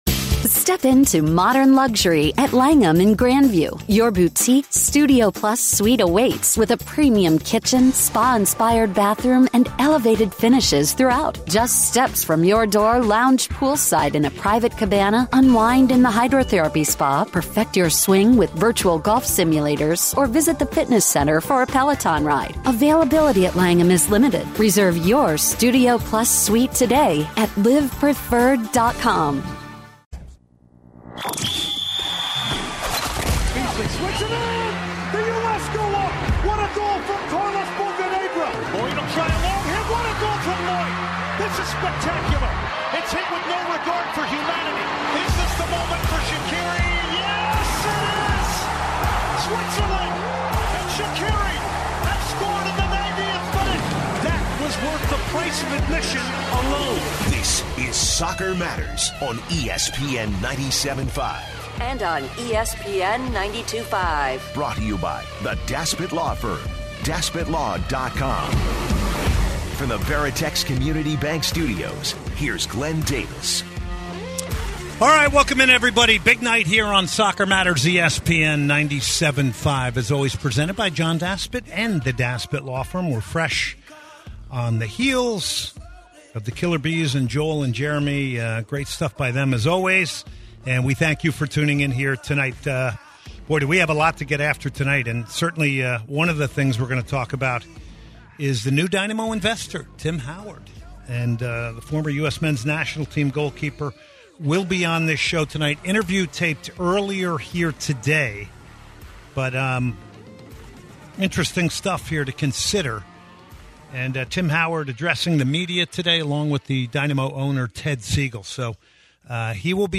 Legendary GK joins Dynamo ownership group, he joins for an interview.